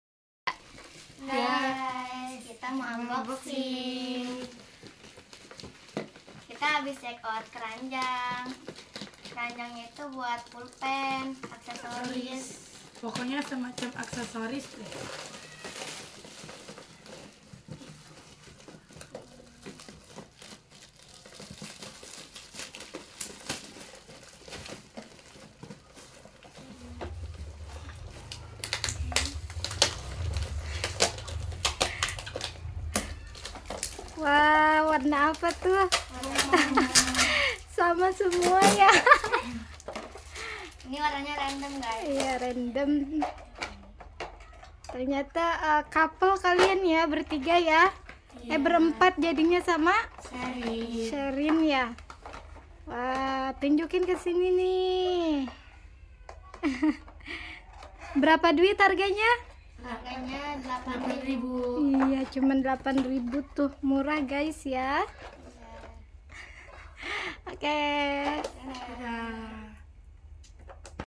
Unboxing Soft Spoken Sound Effects Free Download